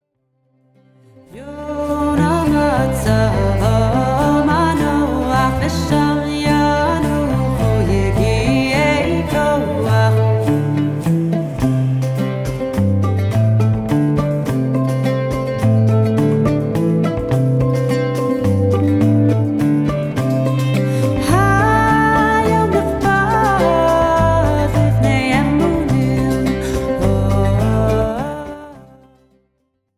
Folk